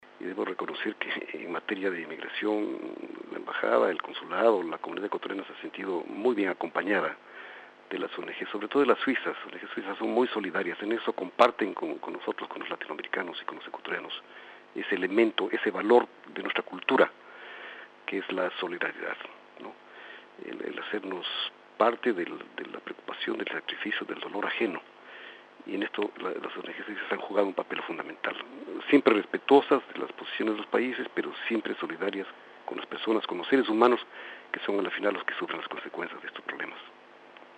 Jaime Marchán, embajador de Ecuador ante Suiza, en entrevista con swissinfo.